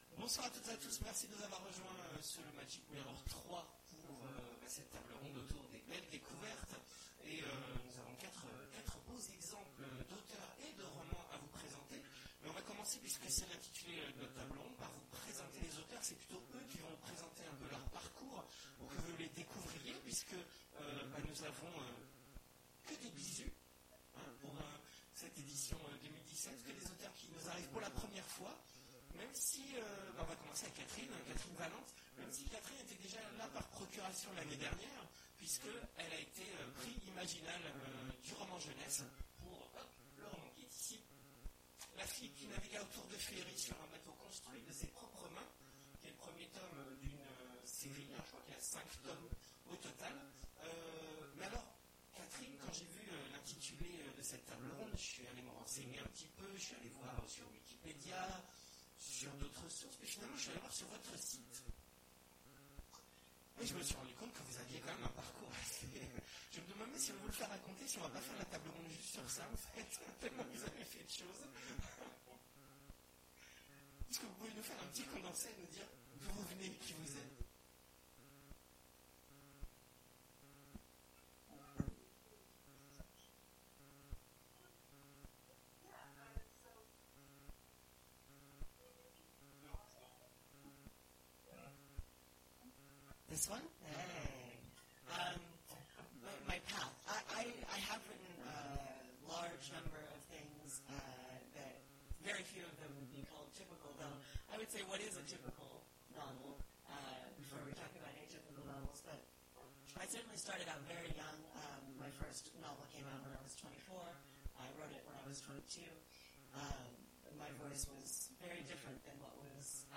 Imaginales 2017 : Conférence Auteurs et romans atypiques…